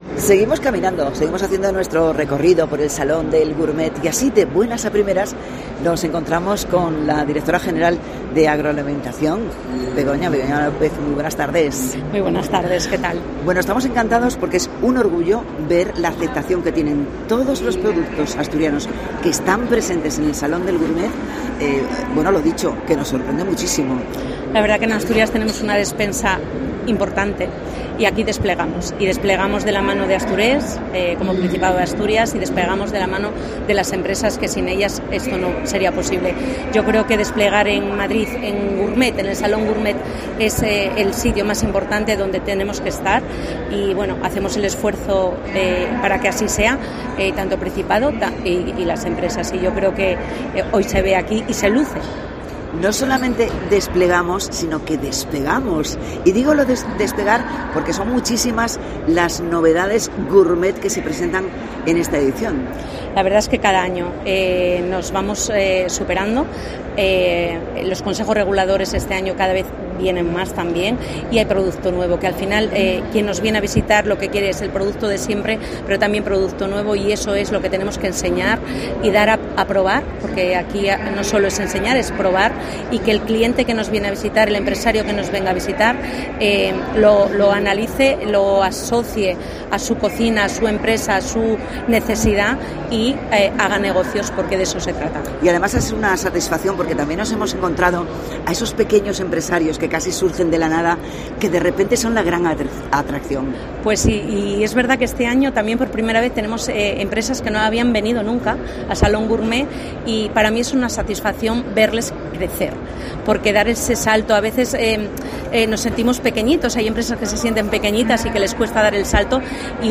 Entrevista a Begoña López, directora general de Agroalimentación del Principado